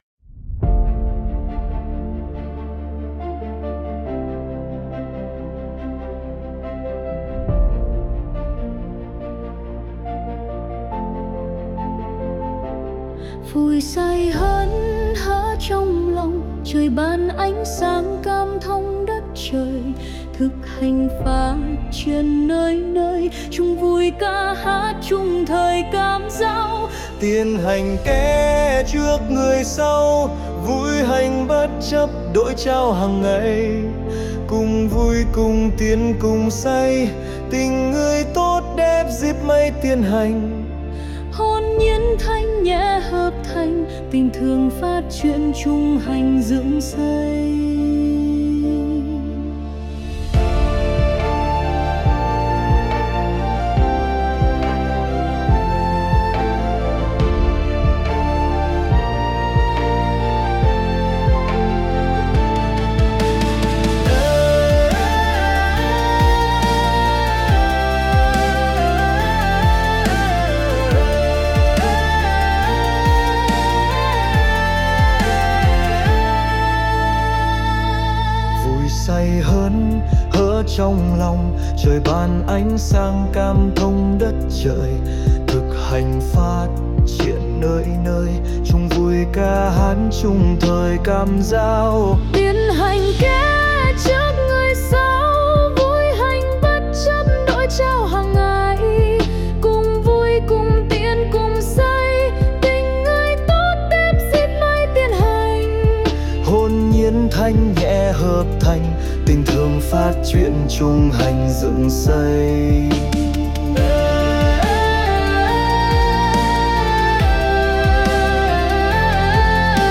180-Tuoi-tre-01-nam-nu.mp3